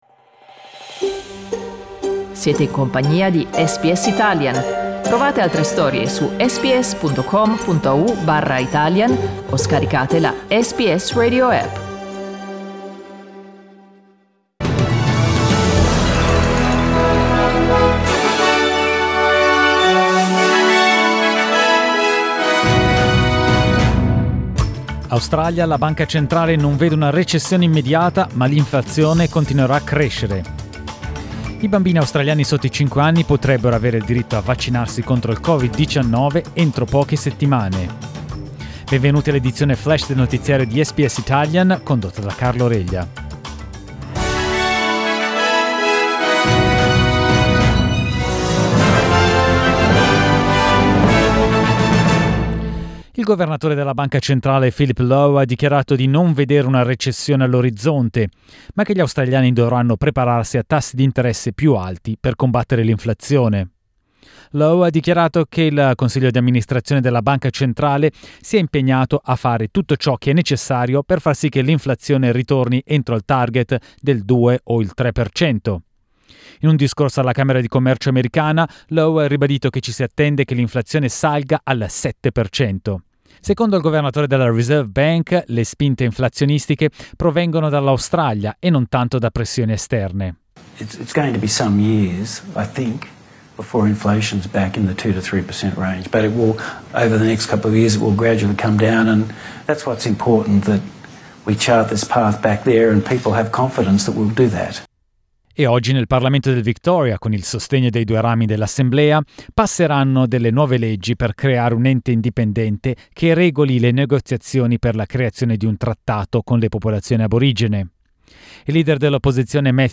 News flash martedì 21 giugno 2022
L'aggiornamento delle notizie di SBS Italian.